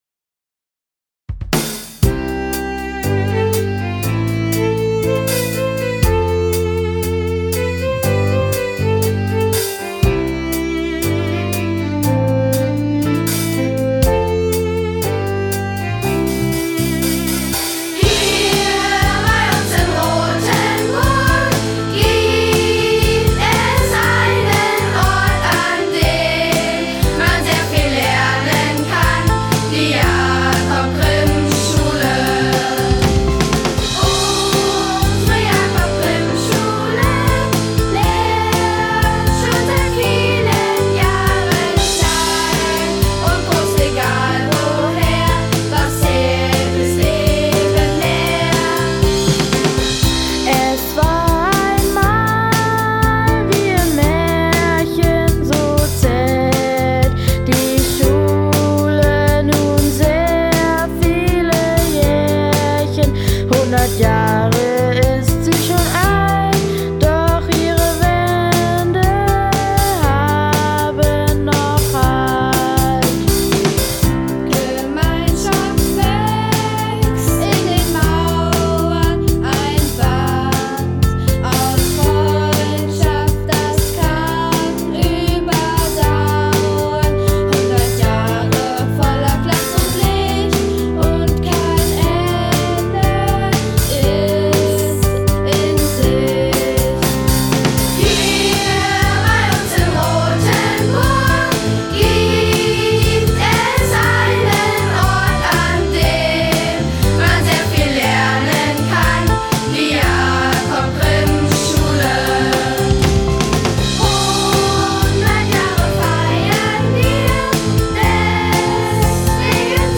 Aufnahme des Schulsongs im Tonstudio - Jakob-Grimm-Schule Rotenburg an der Fulda
zehn Schülerinnen der Chor-AG